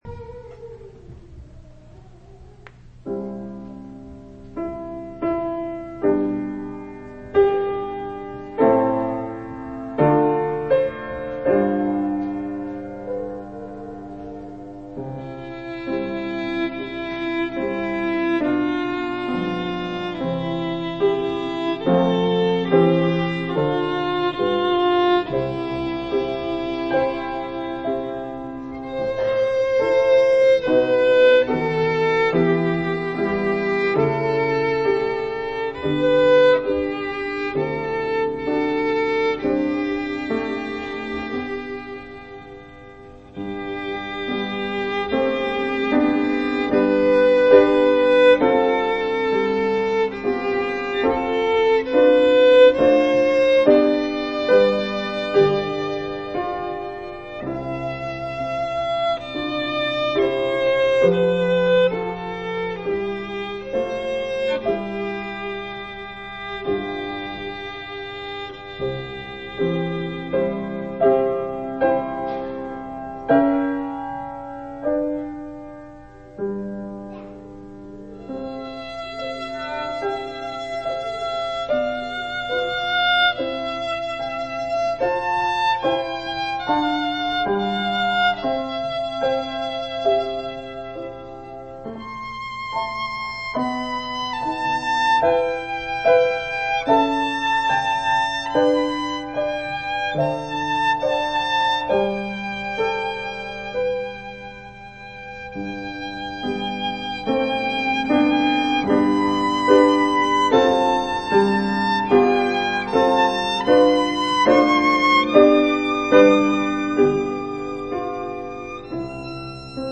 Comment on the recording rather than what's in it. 4/11/2004 Location: Temple Lot Local Event